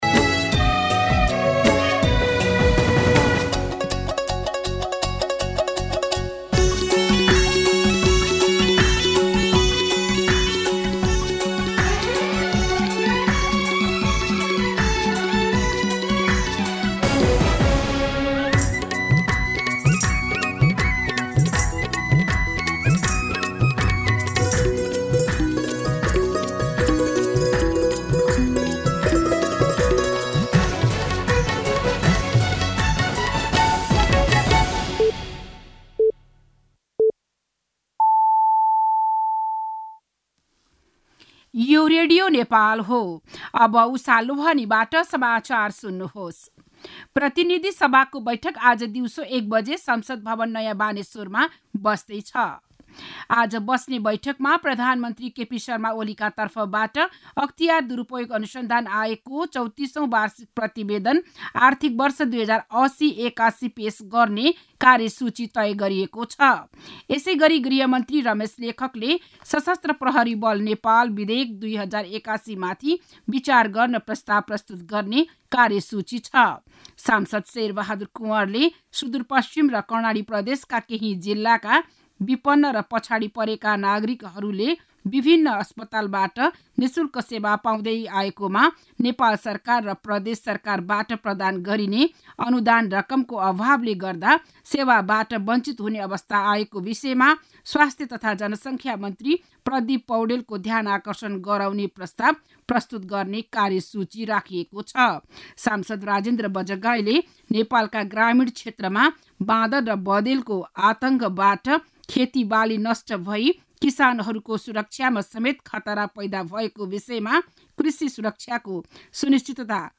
बिहान ११ बजेको नेपाली समाचार : १३ फागुन , २०८१